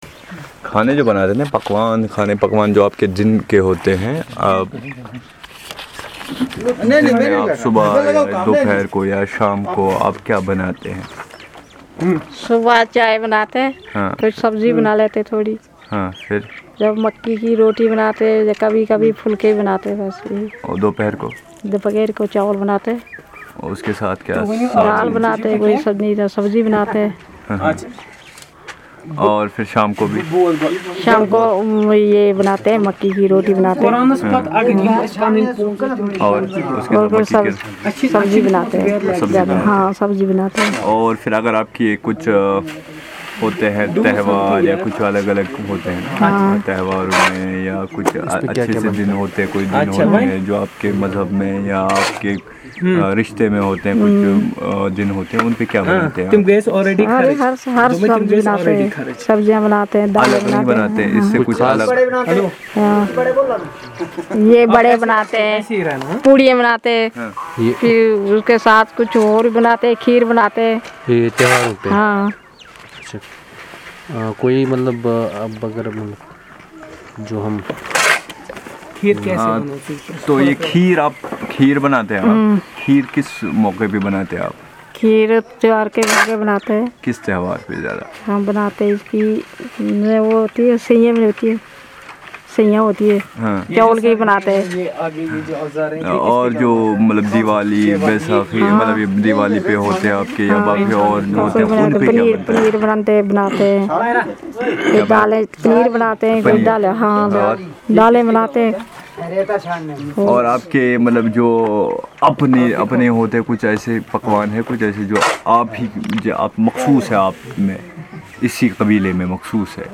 Conversation on the food and beverages consumed by the natives